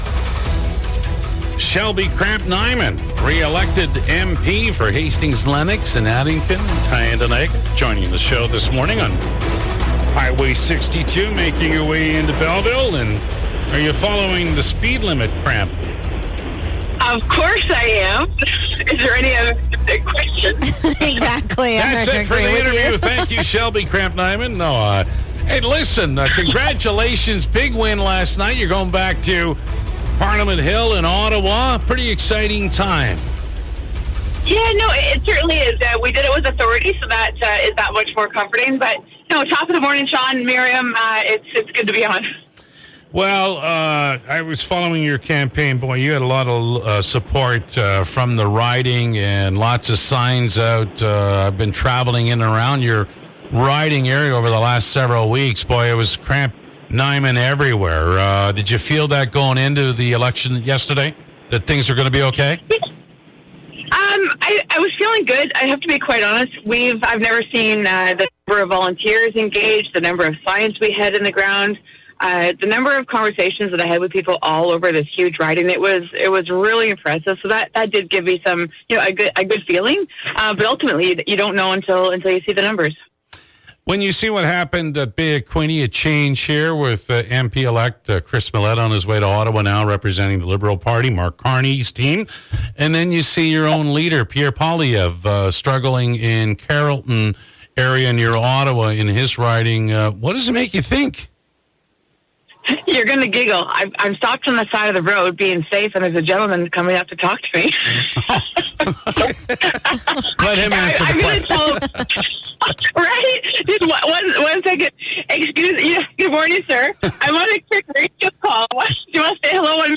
The MIX Morning Crew called Kramp-Neuman to congratulate her on the win!